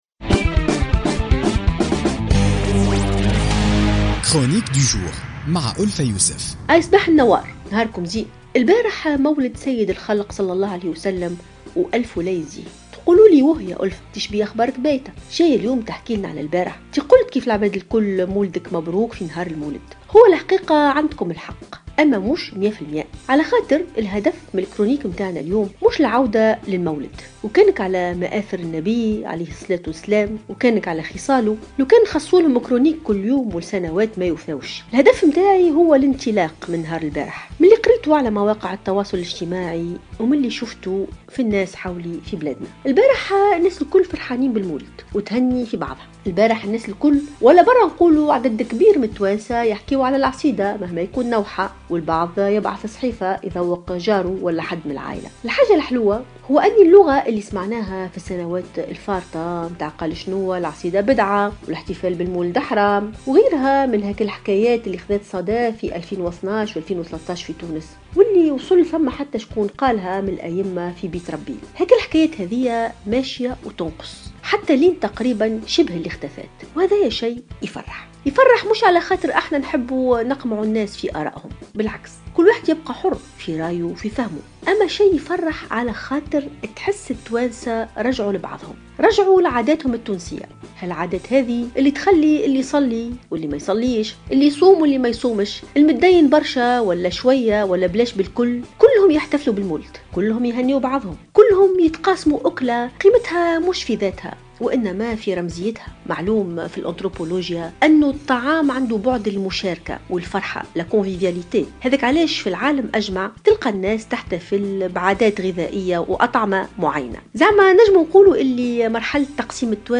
تطرقت الباحثة ألفة يوسف في افتتاحية اليوم الثلاثاء 13 ديسمبر 2016 إلى الإحتفالات بذكرى المولد النبوي الشريف وفرحة التونسيين به وتهنئتهم لبعضهم البعض على مواقع التواصل الإجتماعي وفي الواقع .